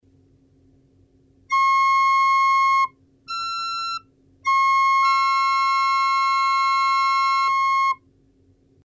2 - Accord de tierce LA4 et DO#5.
Les deux notes ensembles, on entend une résultante LA2